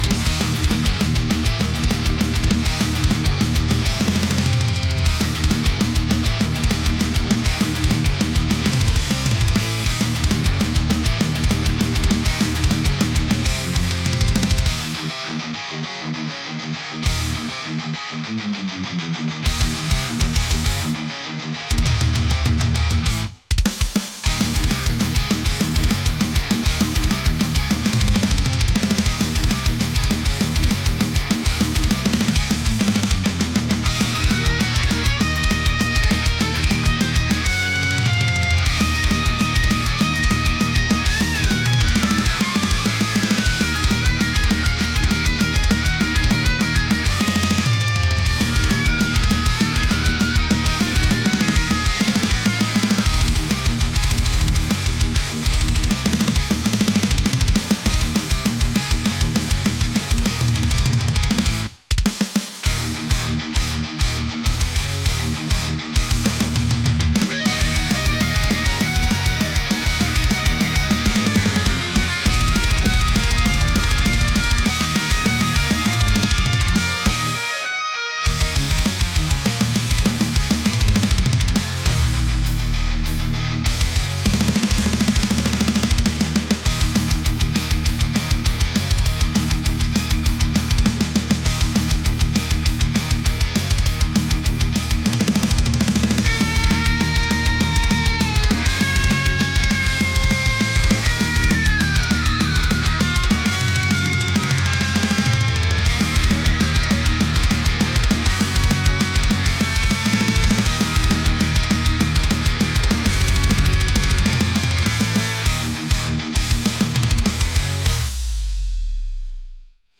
intense | metal | aggressive